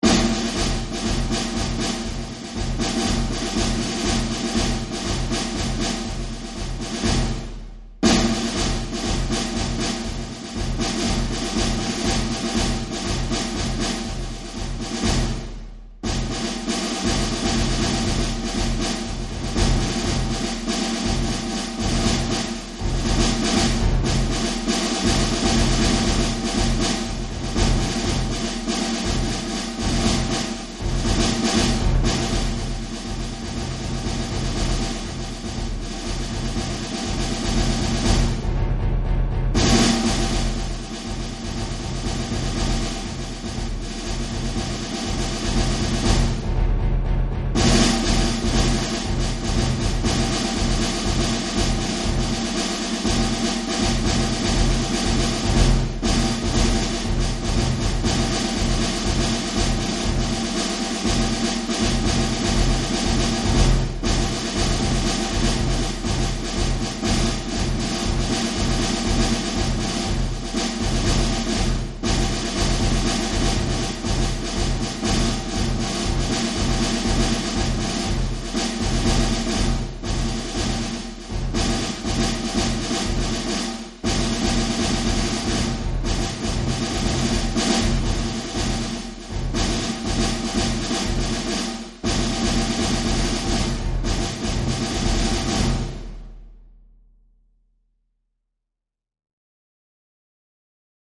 Jeugd Ensemble
Snare drum Bass drum